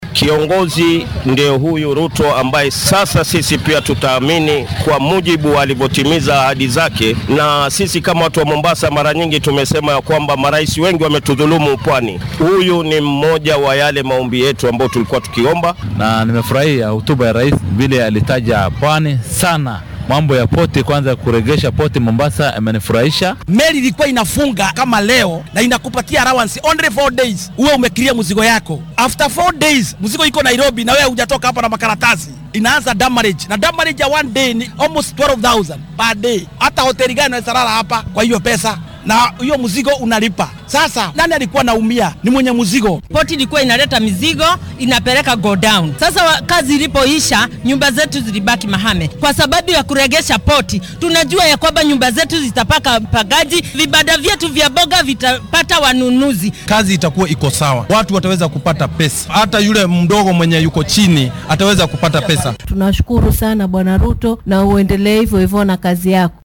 Shacabka Mombasa qaar ka mid ah oo arrintan u riyaaqay ayaa dareenkooda la wadaagay warbaahinta.